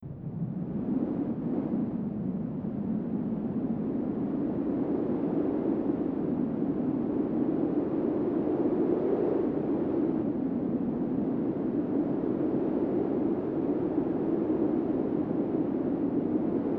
Wind Sound in Market
SoftWind.wav